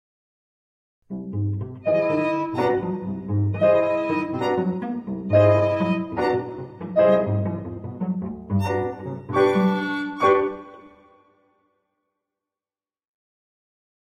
(1999) for clarinet, violin, cello, and piano. 3 minutes.